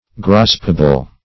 Graspable \Grasp"a*ble\, a.